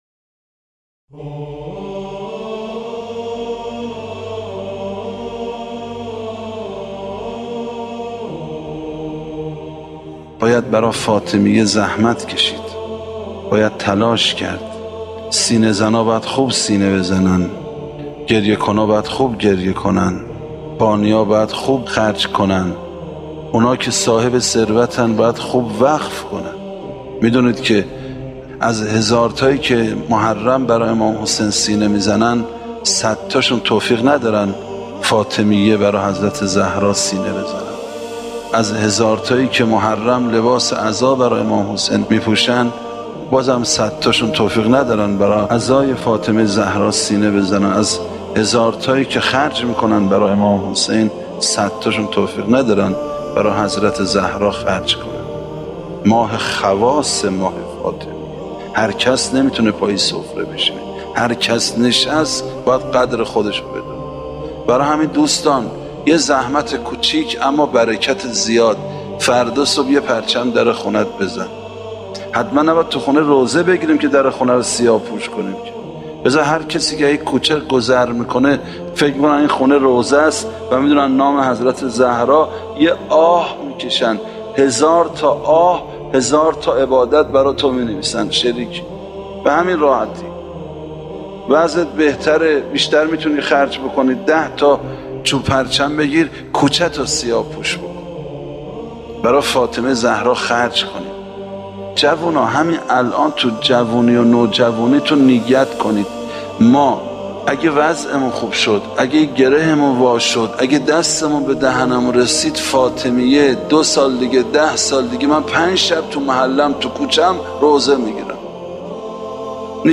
شهادت حضرت زهرا(س) آموزه‌ای متعالی در باب دفاع از آرمان‌های دینی و ارزش‌های اسلام است. ایکنا به مناسبت ایام سوگواری شهادت دخت گرامی آخرین پیام‌آور نور و رحمت، مجموعه‌ای از سخنرانی اساتید اخلاق کشور درباره شهادت ام ابیها(س) را با عنوان «ذکر خیر ماه» منتشر می‌کند.